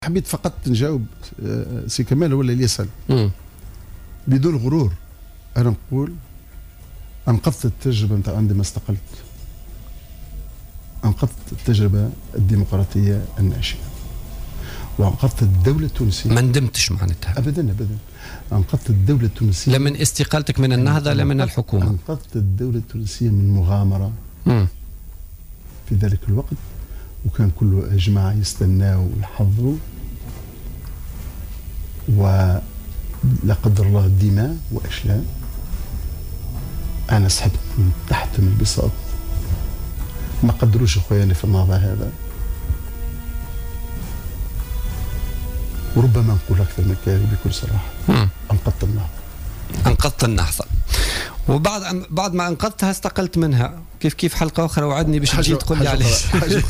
Lors de son passage sur les ondes de Jawhara FM dans le cadre de l’émission Politica du vendredi 14 août 2015, l’ex-secrétaire général d’Ennahdha Hamadi Jebali, a indiqué qu’il a sauvé la démocratie tunisienne, l’Etat tunisien et le mouvement Ennahdha en démissionnant de son poste.